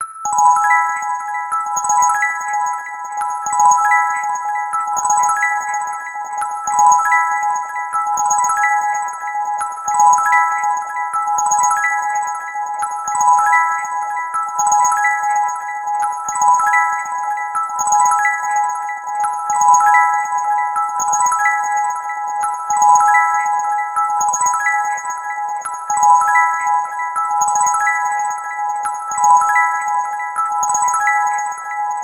オルゴールの音色の優しい着信音。